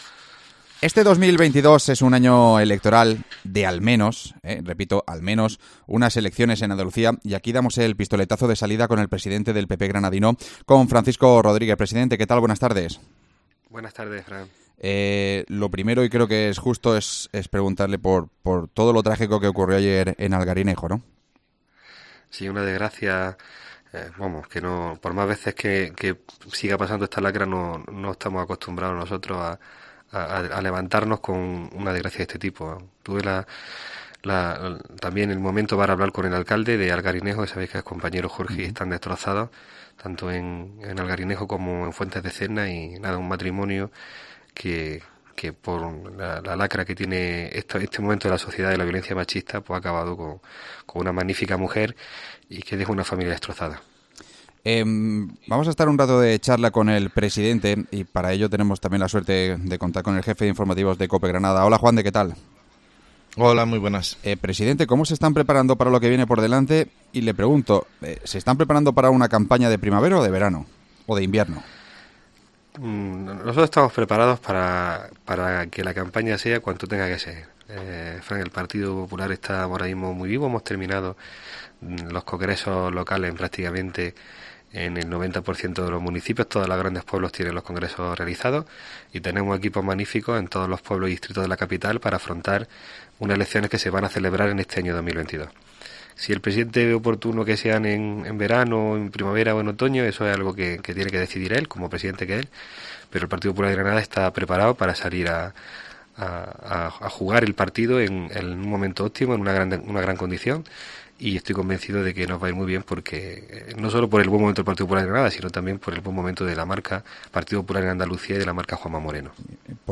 AUDIO: El presidente del PP granadino, Francisco Rodríguez, ha pasado por COPE Granada para analizar la actualidad política